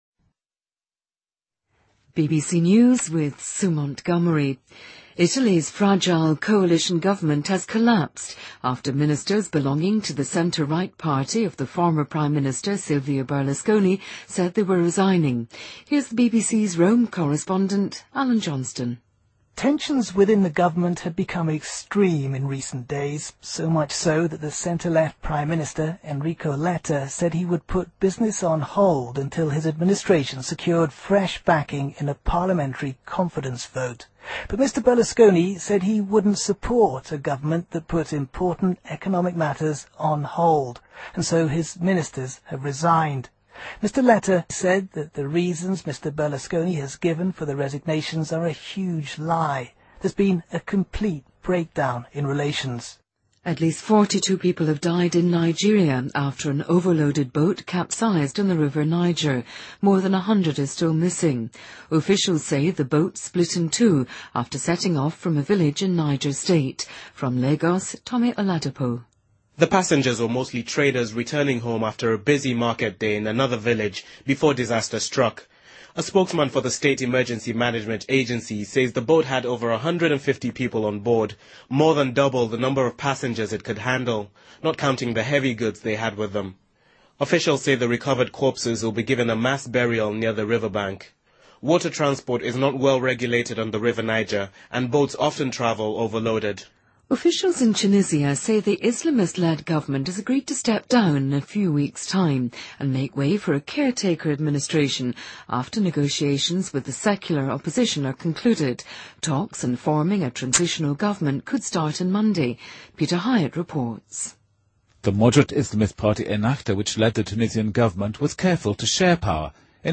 Date:2013-09-29Source:BBC Editor:BBC News